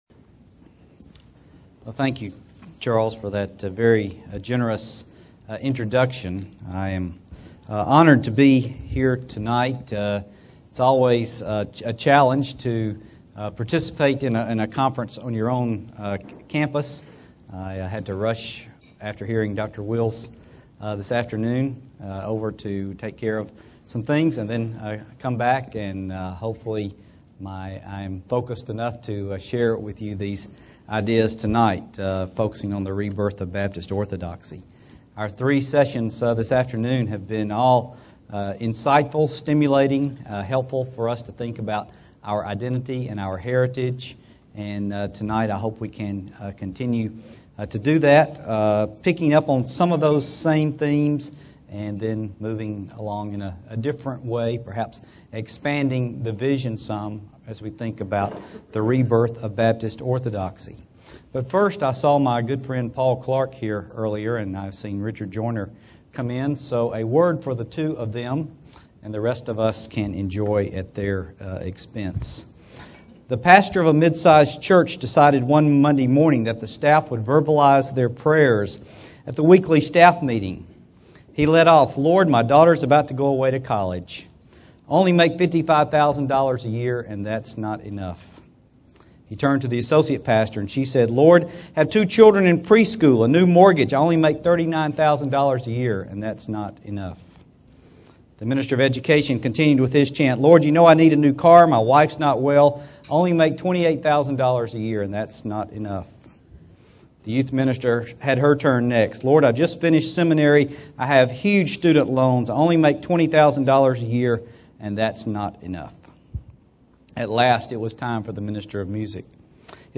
Baptist Identity Conference